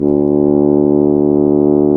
BRS F HRN 00.wav